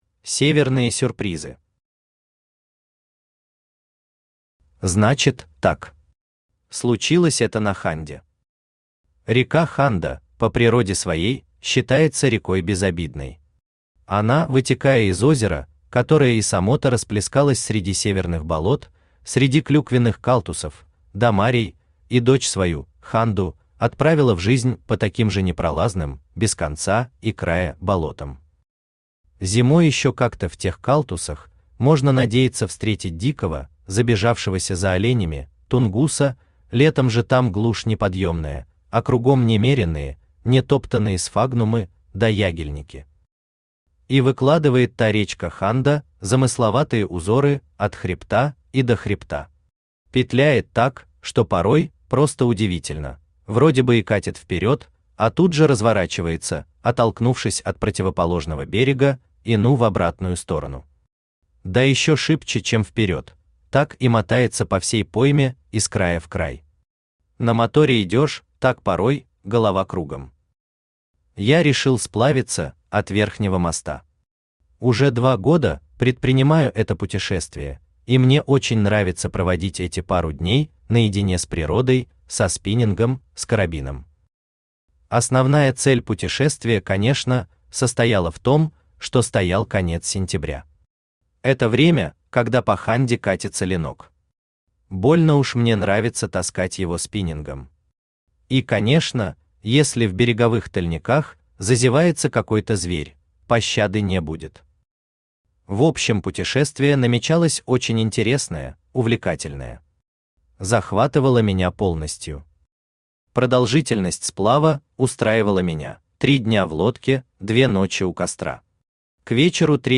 Аудиокнига Северные сюрпризы | Библиотека аудиокниг
Aудиокнига Северные сюрпризы Автор Андрей Андреевич Томилов Читает аудиокнигу Авточтец ЛитРес.